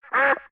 duck_1.ogg